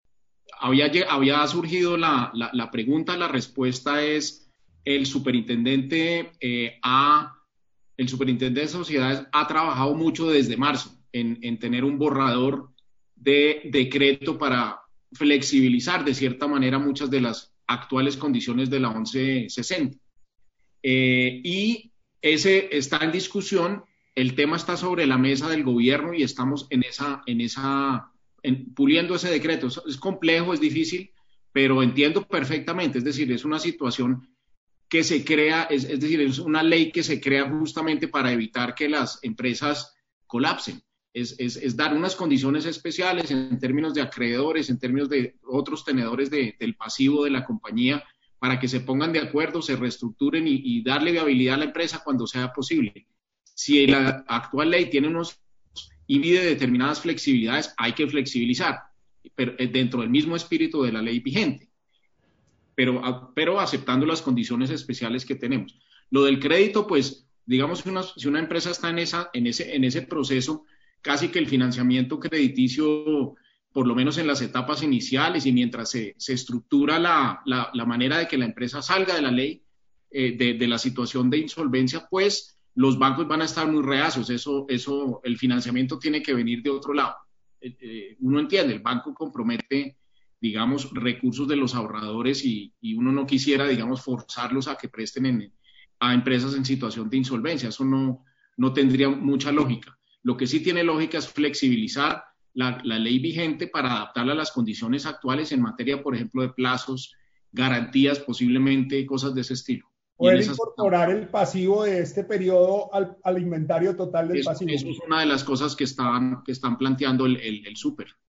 Stereo
ministro-panel-economia-bioseguridad-part3-1